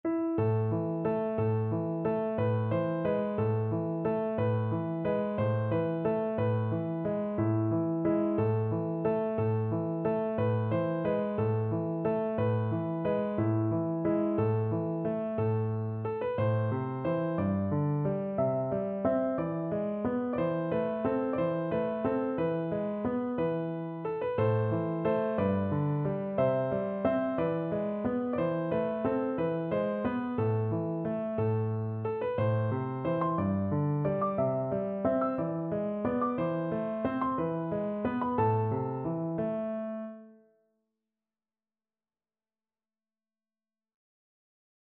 No parts available for this pieces as it is for solo piano.
C major (Sounding Pitch) (View more C major Music for Piano )
6/8 (View more 6/8 Music)
~ = 90 Munter
Piano  (View more Easy Piano Music)
Classical (View more Classical Piano Music)
marmotte_PNO.mp3